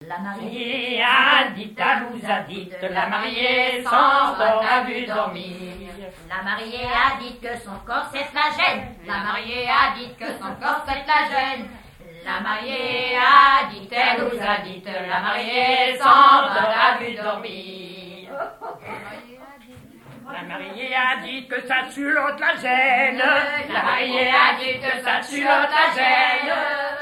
danse : ronde : demi-rond
chansons tradtionnelles
Pièce musicale inédite